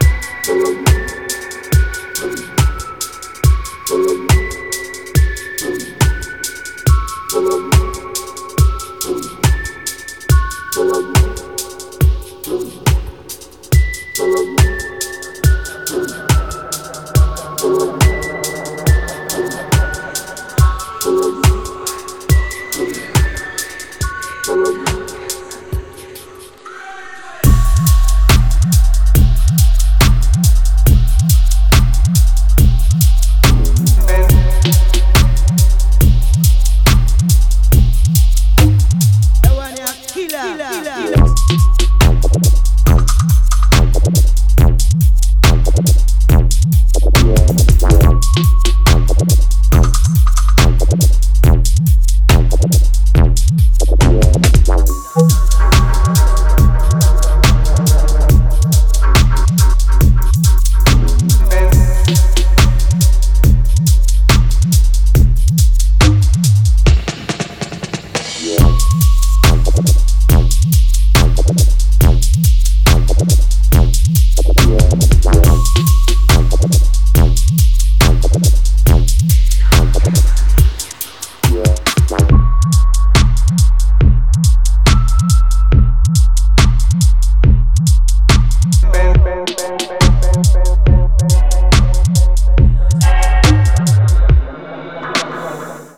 Dub Reggae Bass